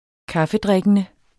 Udtale [ ˈkɑfəˌdʁεgənə ]